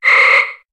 Cri de Kirlia dans Pokémon HOME.